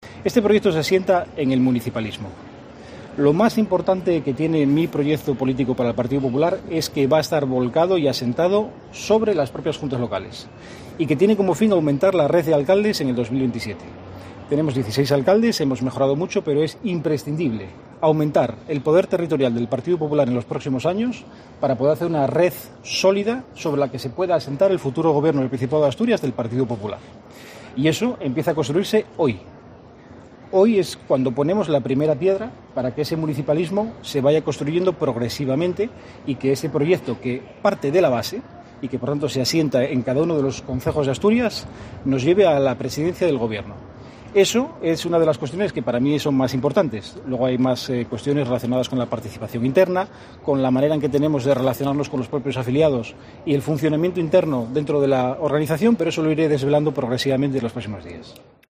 El principal aspirante a liderar el PP de Asturias ha iniciado su campaña en Oviedo en un acto con las Nuevas Generaciones del partido